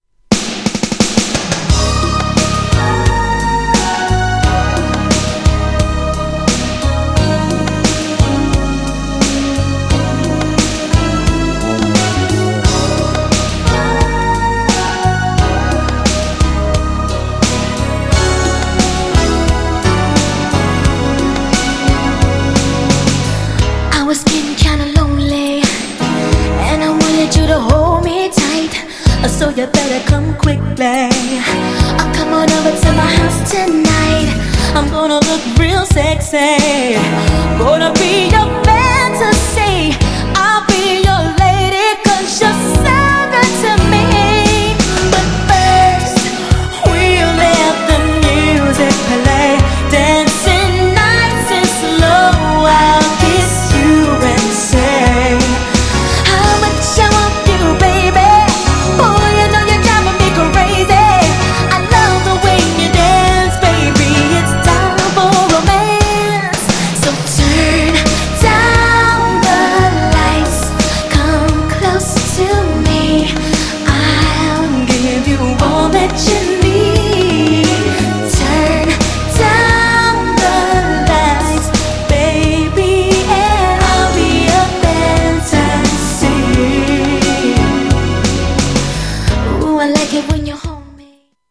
GENRE R&B
BPM 106〜110BPM